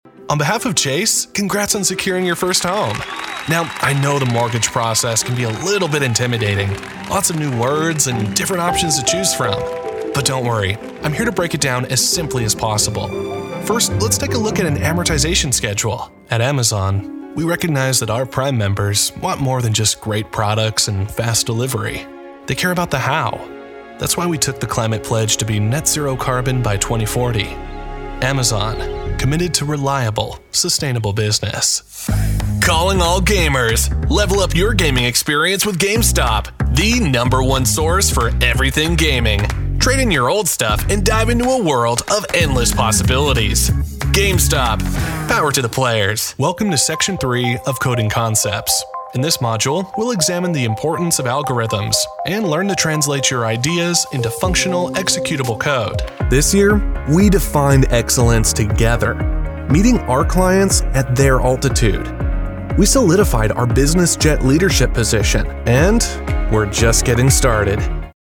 American Voice Over Talent
Adult (30-50) | Yng Adult (18-29)